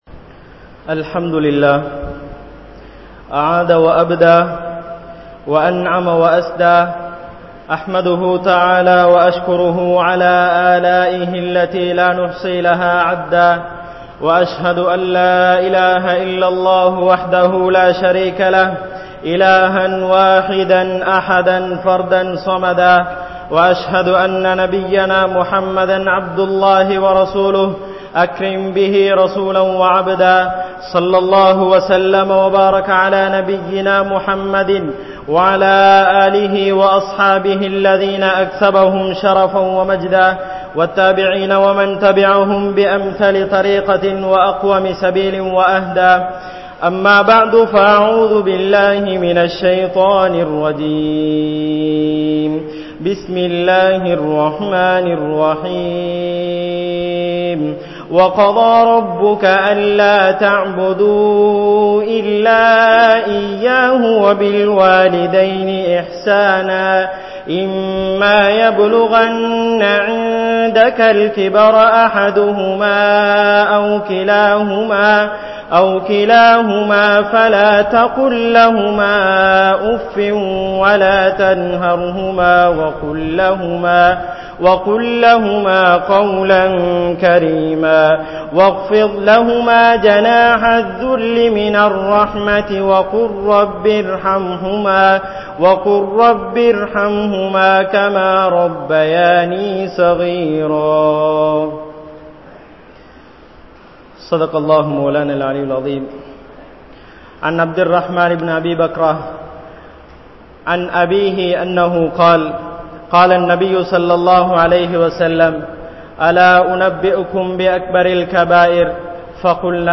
Pettroarhalai Maranthu Vidaatheerhal (பெற்றோர்களை மறந்து விடாதீர்கள்) | Audio Bayans | All Ceylon Muslim Youth Community | Addalaichenai
Gorakana Jumuah Masjith